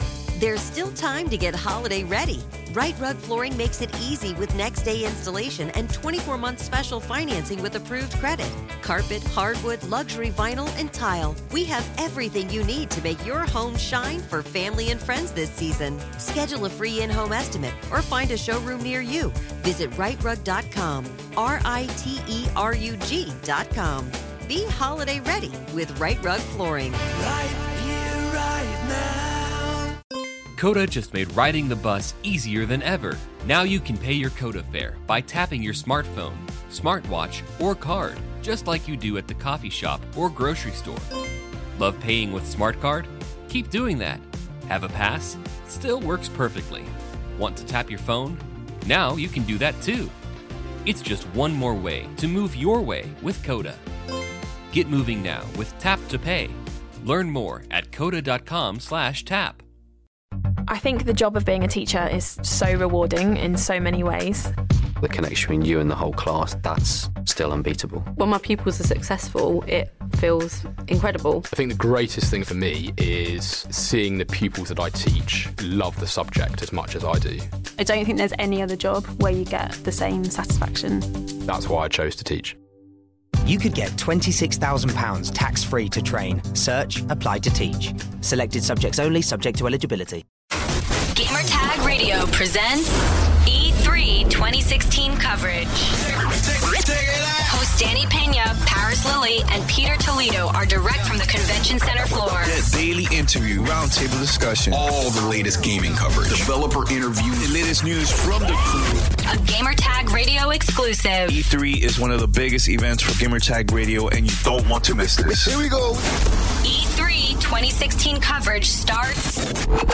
E3 2016: Ghost Recon Wildlands Interview